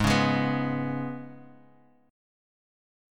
G#mbb5 chord